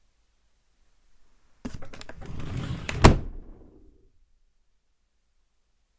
Fast/Close End Drawer
Duration -6 s Environment - Bedroom, absorption of curtains, carpet and bed. Description - Open, pulled slowly wooden drawer...